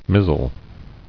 [miz·zle]